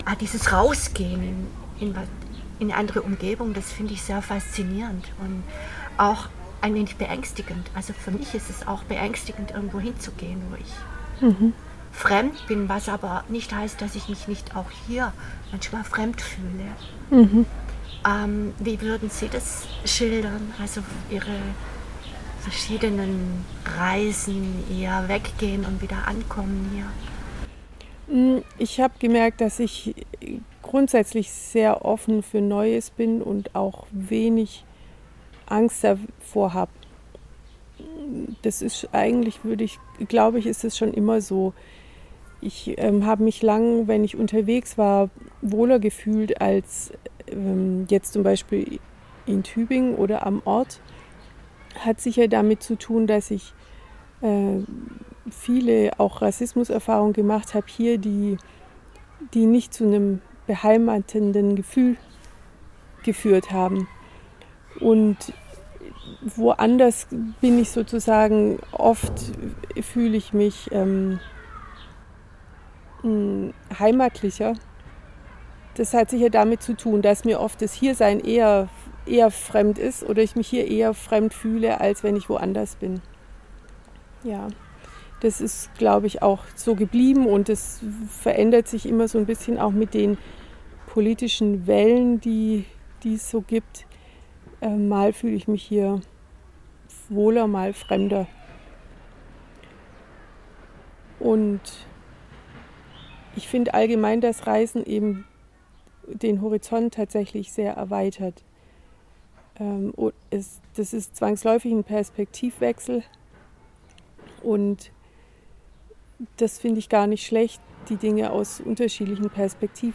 In ihrem Garten sprachen wir in diesem Sommer über ihren Werdegang